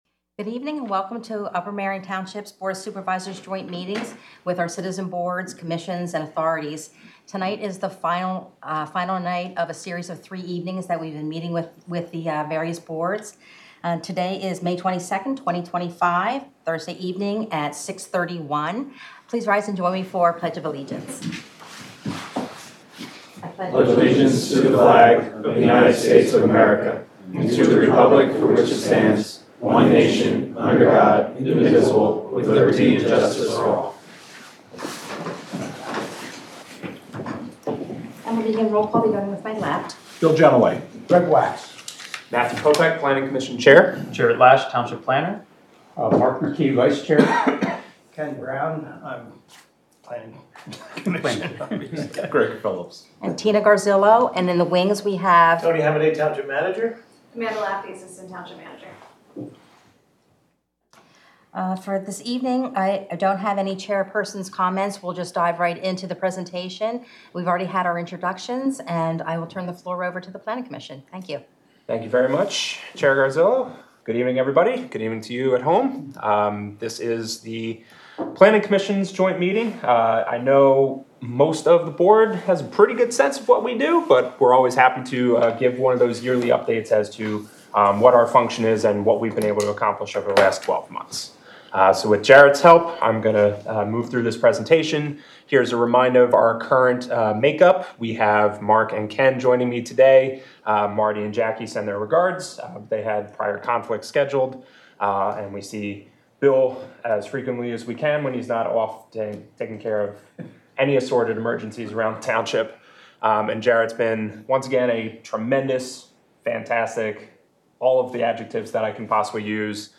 BOS Joint Meeting - Planning Commission